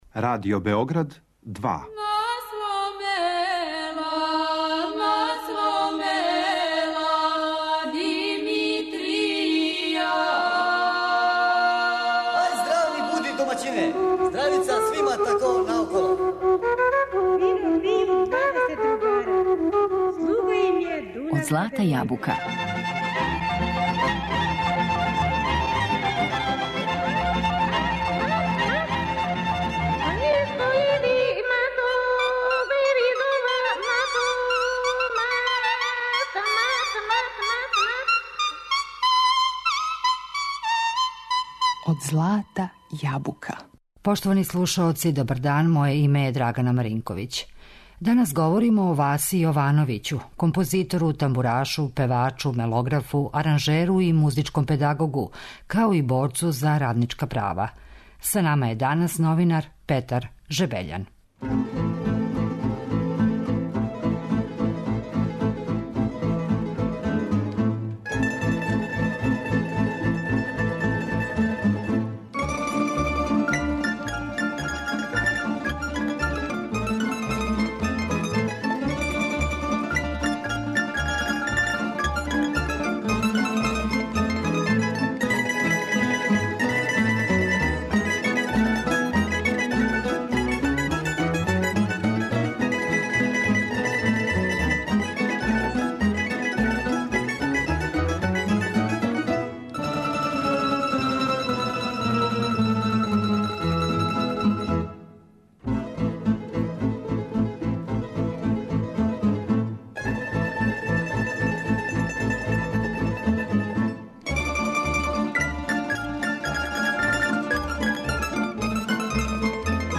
Композиције које ћемо чути у данашњој емисији, снимио је Велики тамбурашки оркестар Радио-телевизије Војводине.